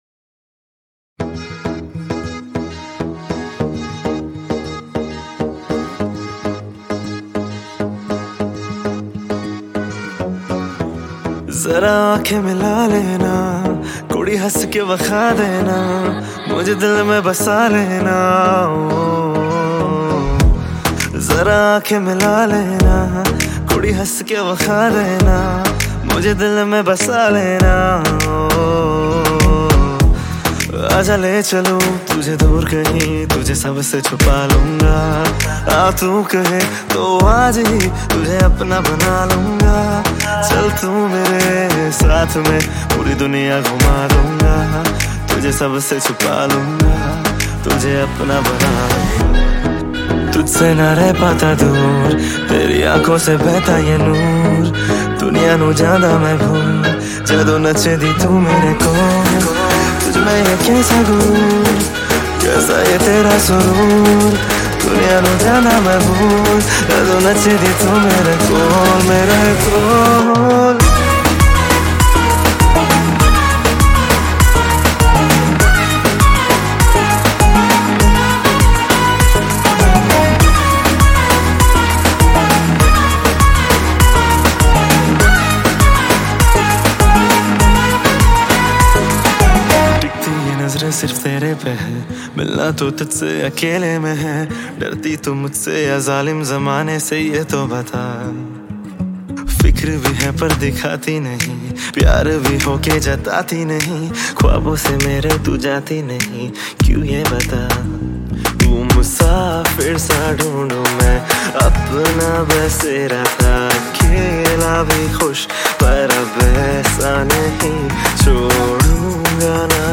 Punjabi Bhangra MP3 Songs
Indian Pop